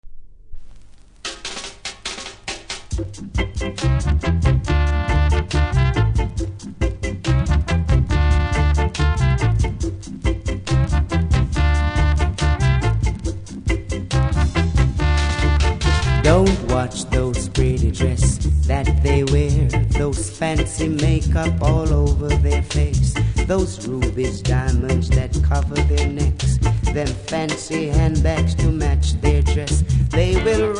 REGGAE 70'S
多少キズあり多少ノイズ感じます。